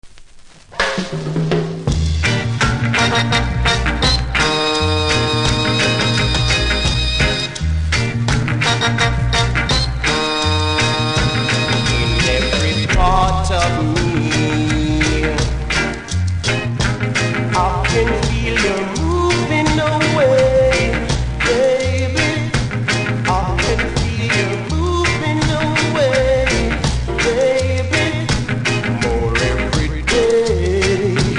両面カバー・ソング♪
ジャマイカ盤なので無録音部分にプレスノイズ感じますが音が始まれば気付かないレベル。